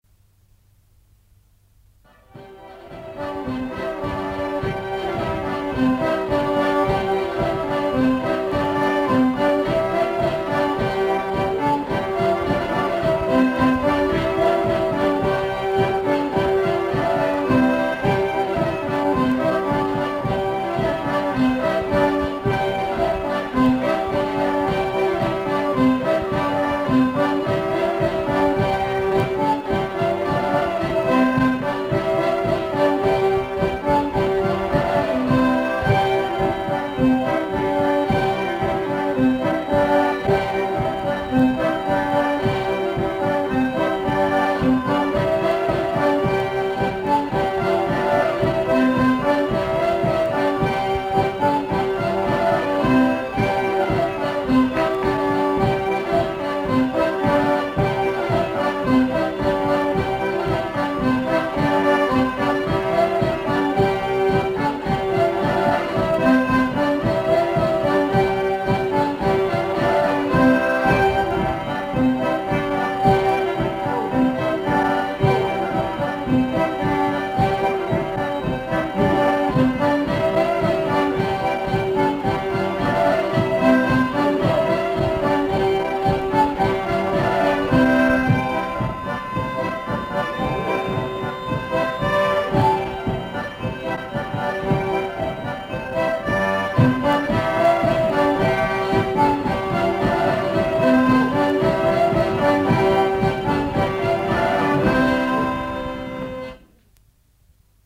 Aire culturelle : Savès
Département : Gers
Genre : morceau instrumental
Instrument de musique : accordéon diatonique ; violon ; vielle à roue
Danse : rondeau